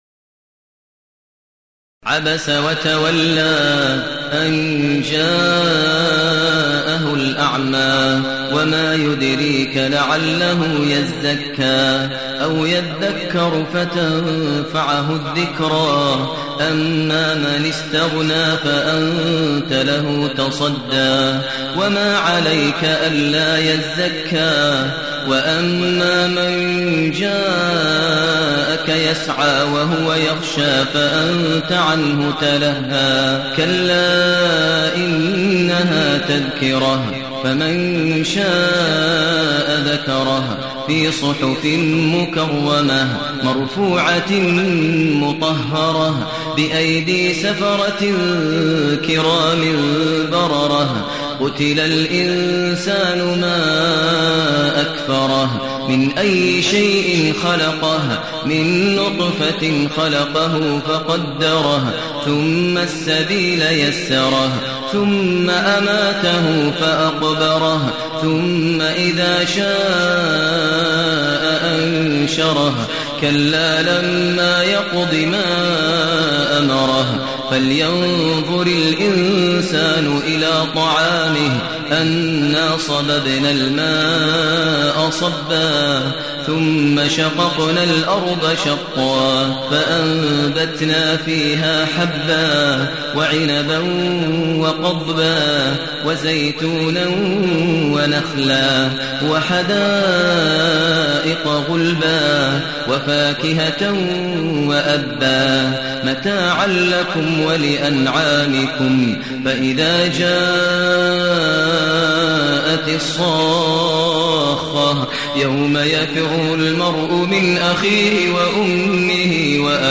المكان: المسجد النبوي الشيخ: فضيلة الشيخ ماهر المعيقلي فضيلة الشيخ ماهر المعيقلي عبس